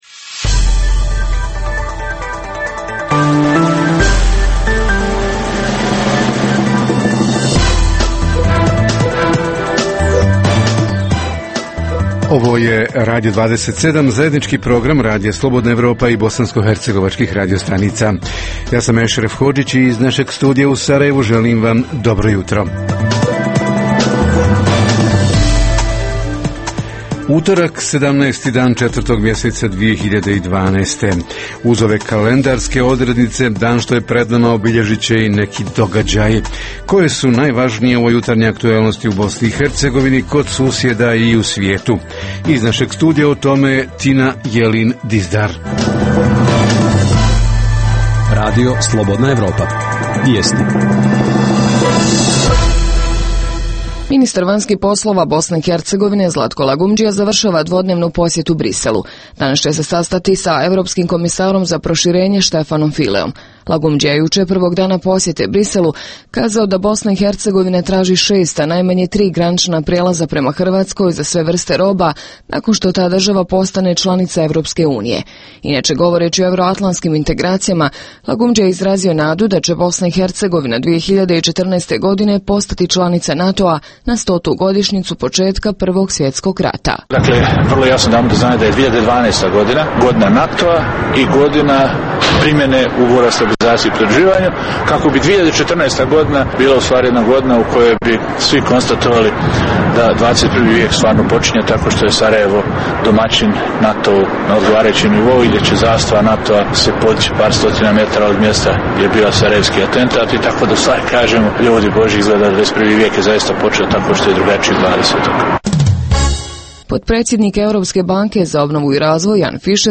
Tema jutarnjeg programa: socijalna prava – pravo na jednokratnu pomoć, na dječiji doplatak, porodiljsku naknadu, pravo na subvenciju grijanja i utroška električne energije i druga socijalna prava – kako se stiču i kako se ostvaruju? Reporteri iz cijele BiH javljaju o najaktuelnijim događajima u njihovim sredinama.
Redovni sadržaji jutarnjeg programa za BiH su i vijesti i muzika.